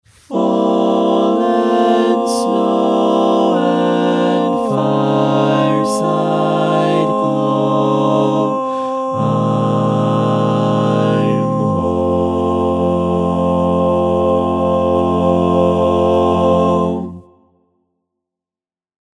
Key written in: F Major
How many parts: 4
Type: Barbershop
All Parts mix:
Learning tracks sung by